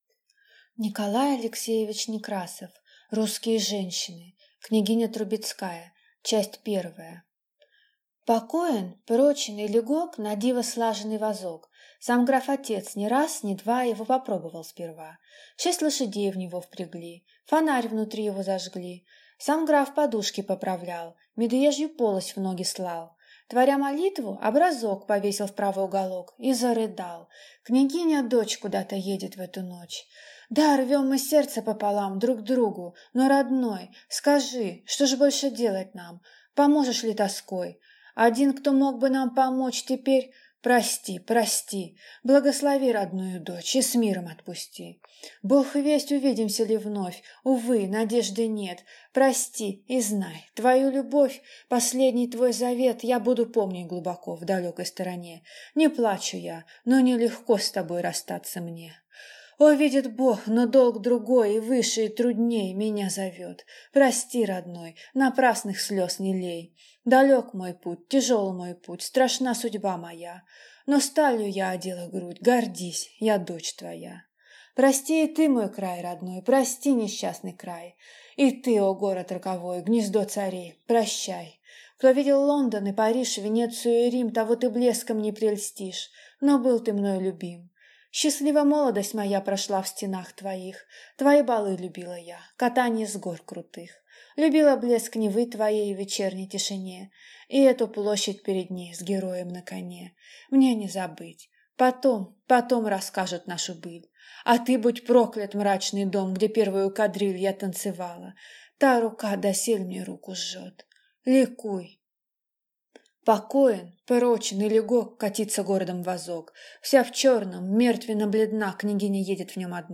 Аудиокнига Русские женщины | Библиотека аудиокниг